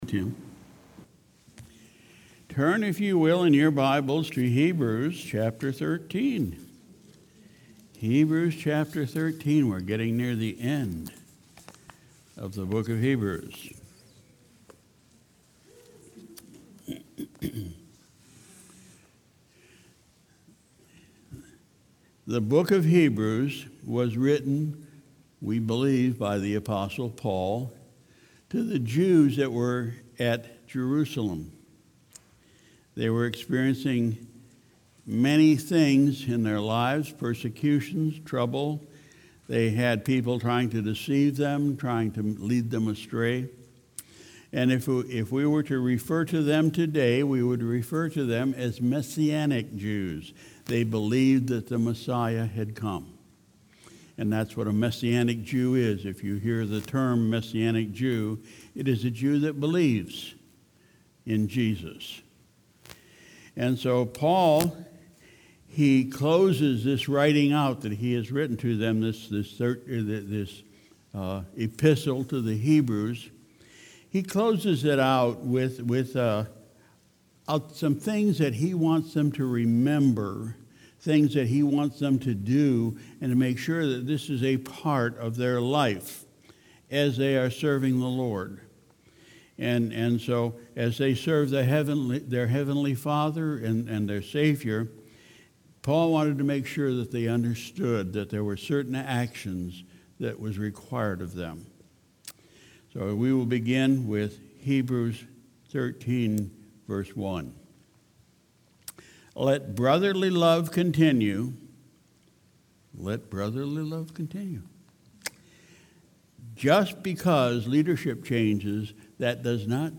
Sunday, August 11, 2019 – Evening Service